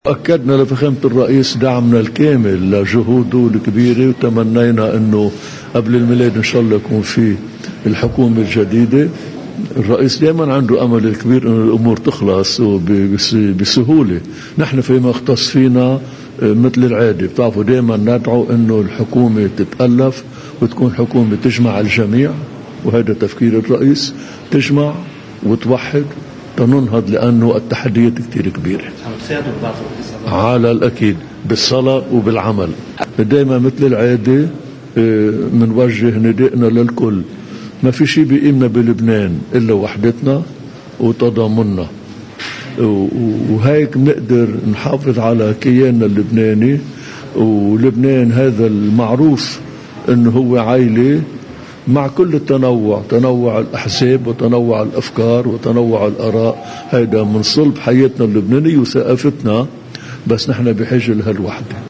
مقتطفات من حديث البطريرك الراعي بعد لقائه الرئيس عون في بعبدا: